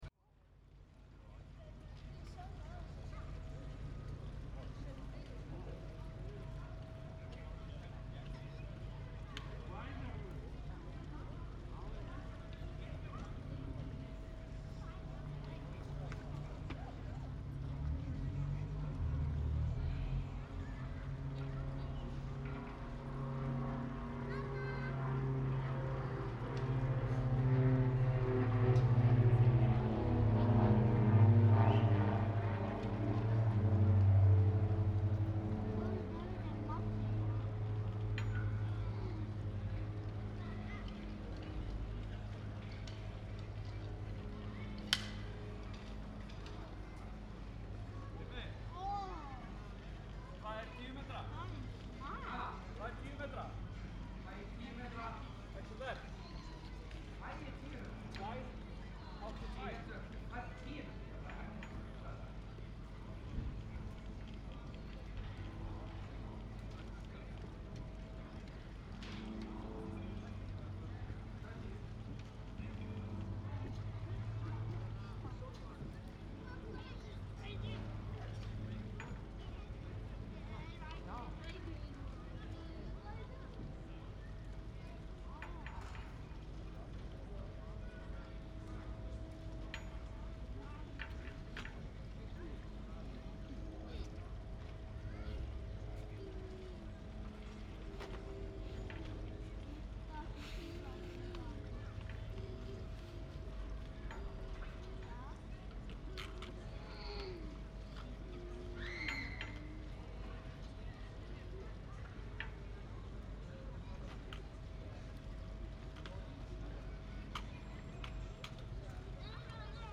Posted in Mannlíf, tagged 1.maí, Baráttudagur verkafólks, Götuupptaka, Hallærisplan, Ingólfstorg, Labour Day, Primo EM172, Sound devices MixPre6, Verkalýðsdagurinn, Verkalýðshreyfingin on 16.5.2023| Leave a Comment »